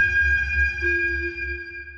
sonarTailWaterFarShuttle1.ogg